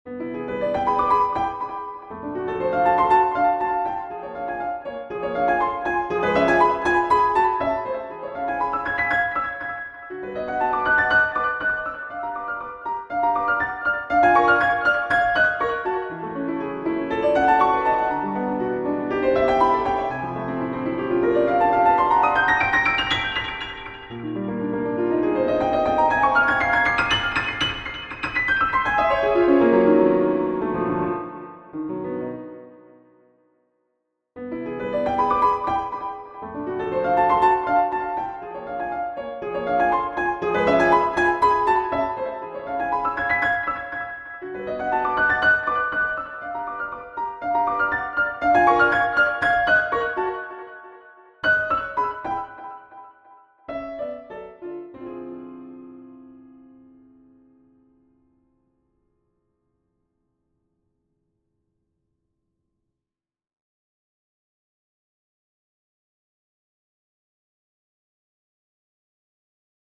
Persichetti Exercise 7 - 51 for Piano